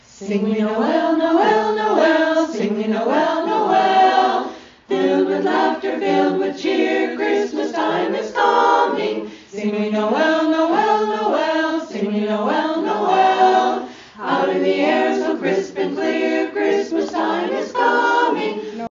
Simply A Cappella - Holiday Singing Grams